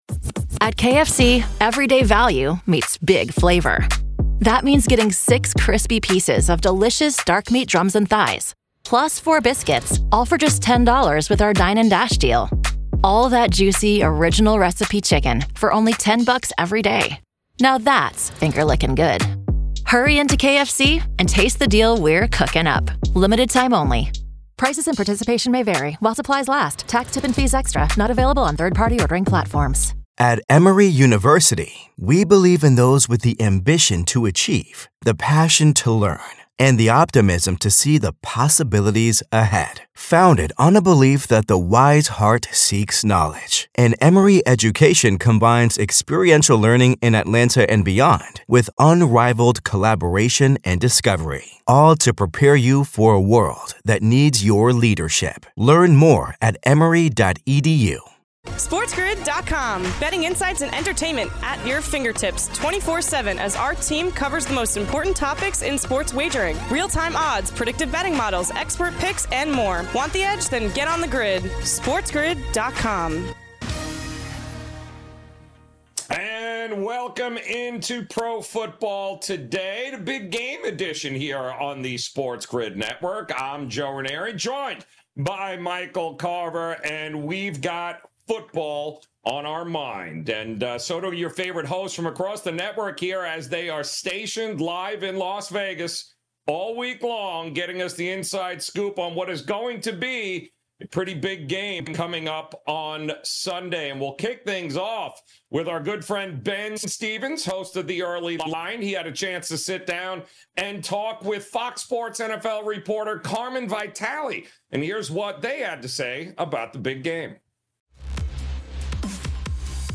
Interviews
and more throughout the hour live from Las Vegas.